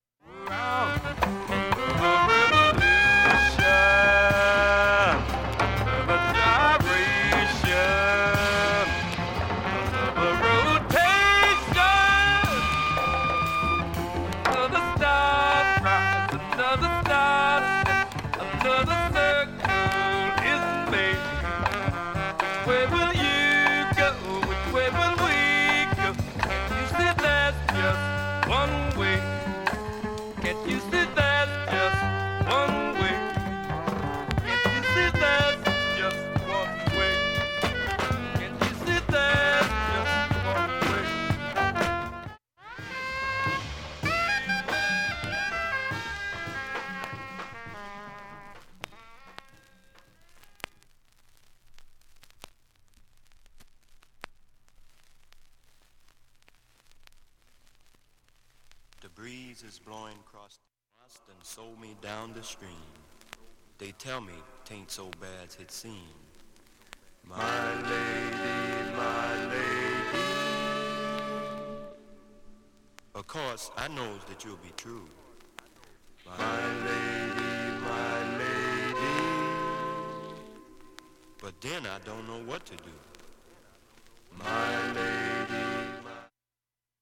A-1B-1始めにかすかなチリ出ますが
ほか無音部もクリアないい音質
A-1序盤にかすかなプツが17回出ます。
全編鋭利にして重厚。
スピリチュアル・ジャズファンク傑作